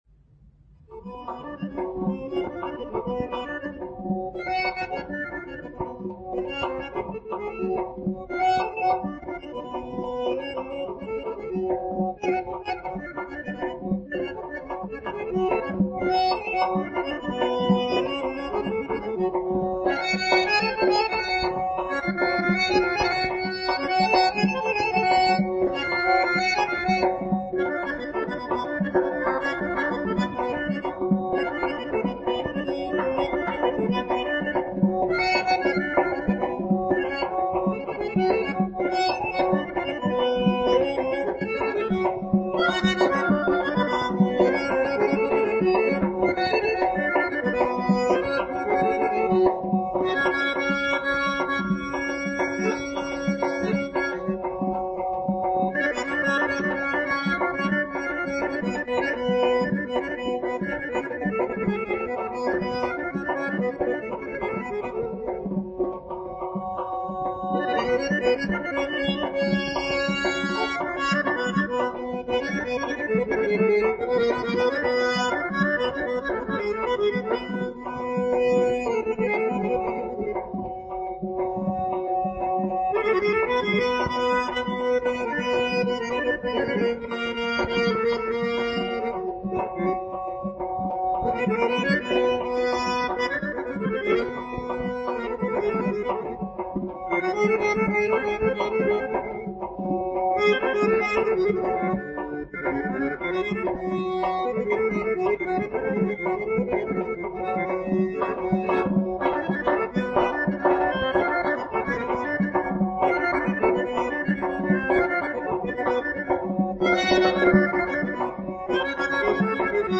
AZƏRBAYCAN XALQ RƏQS MUSİQİSİ
nağara
qarmon
klarnet
balaban.- Qramval №18036.- CD №631.